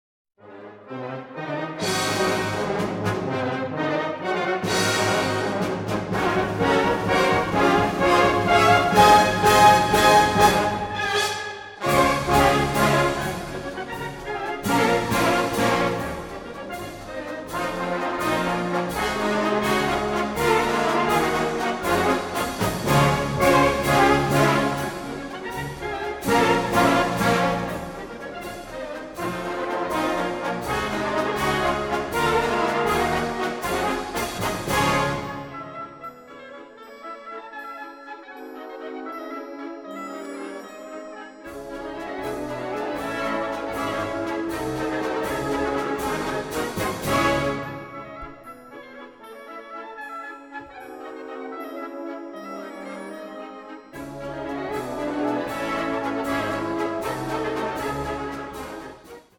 Kategorie Blasorchester/HaFaBra
Unterkategorie Polka
Besetzung Ha (Blasorchester)